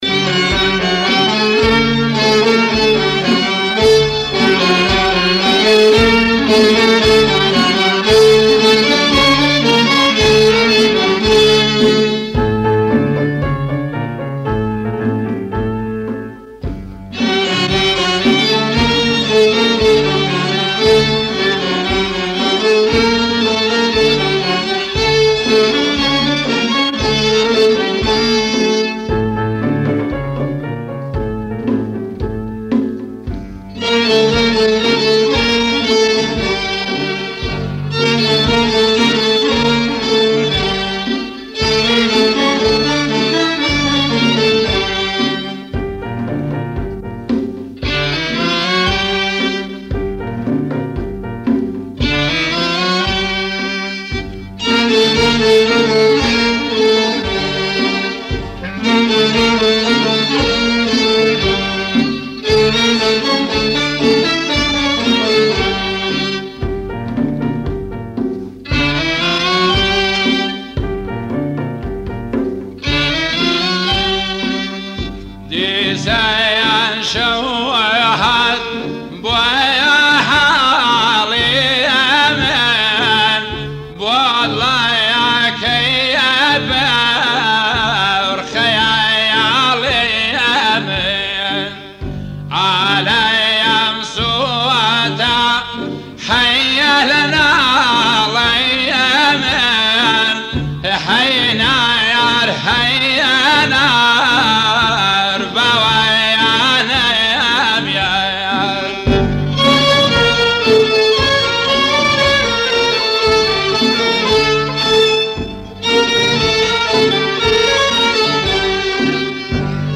آهنگ نوستالژیک کردی آهنگ کوردی قدیمی